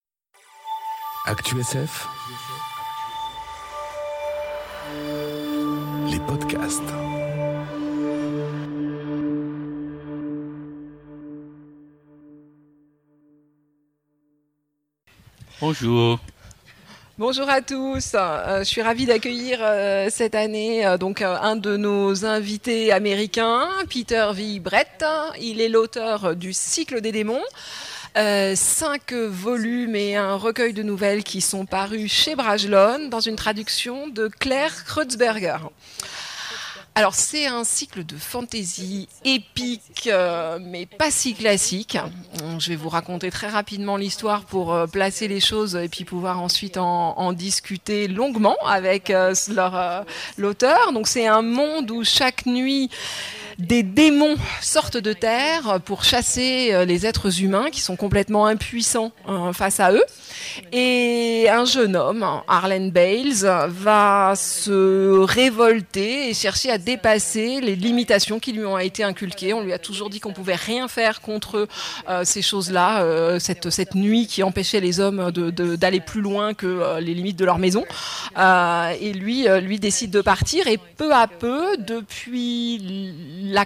Imaginales 2019 : Le grand entretien avec Peter V. Brett